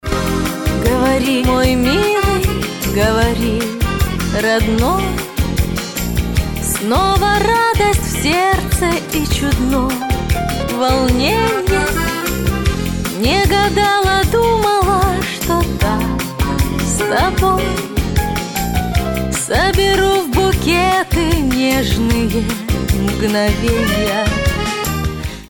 Категория: Нарезки шансона